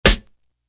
Thisrock Snare.wav